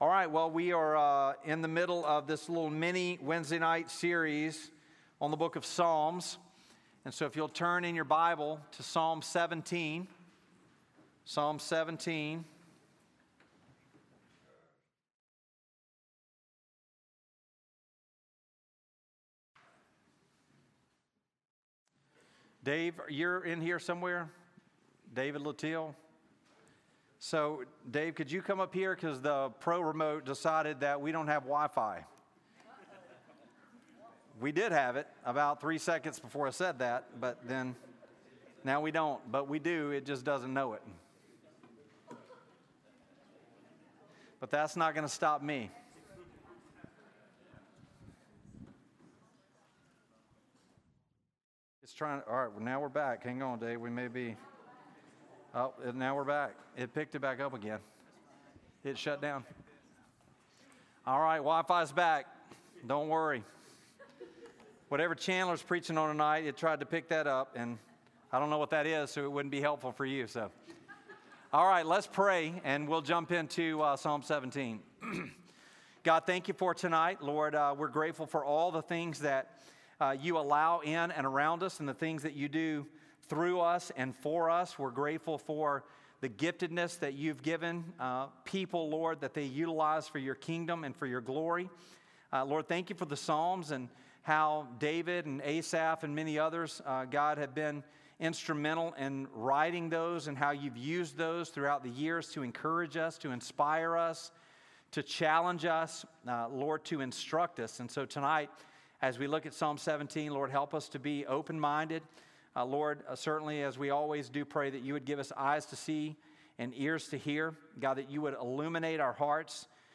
Series: Psalms - Wednesday Family Night Bible Study